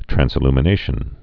(trănsĭ-lmə-nāshən, trănz-)